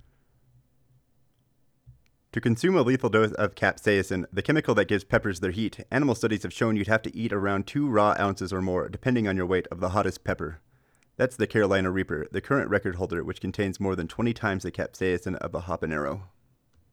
Constant, very quiet clicking
The only other change between this and the last one, other than obviously the reading, is that I disabled the mic on my webcam.
Perfect levels, no room echo, very low background noise (although I can still hear you beating on your desk), good presence and narration swing.
I’m going to call that good on background noise, basically -63dB after processing.
Noise will go down more if you stop that oak/walnut woody rumble noise.